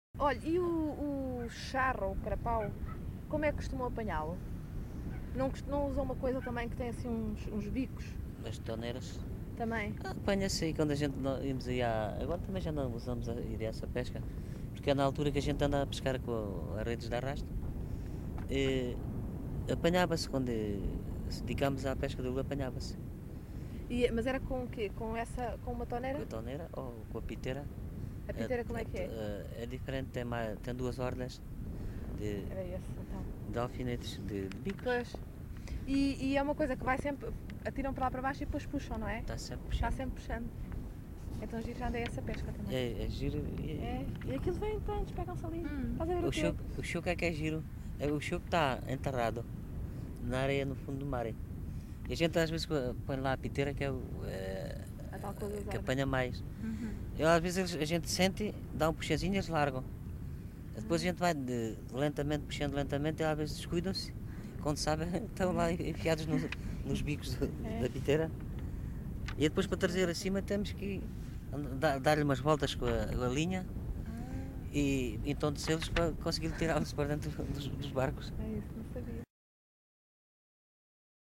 LocalidadeMelides (Grândola, Setúbal)